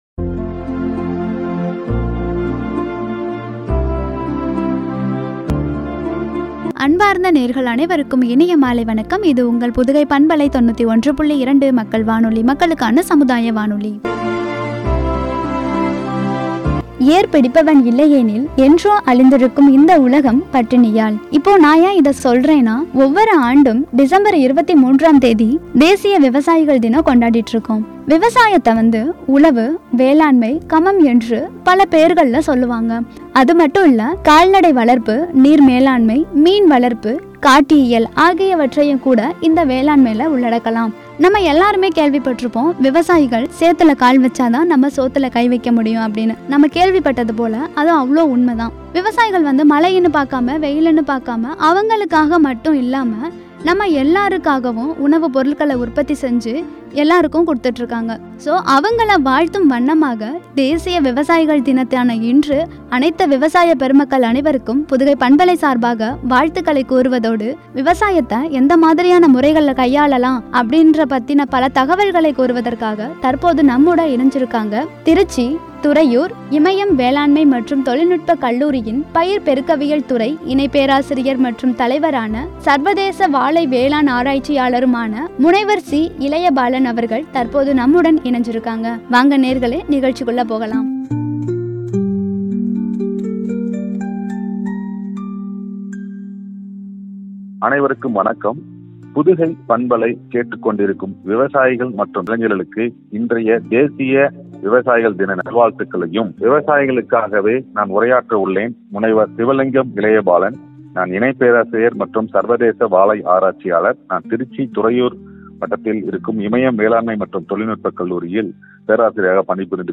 என்ற தலைப்பில் வழங்கிய உரை.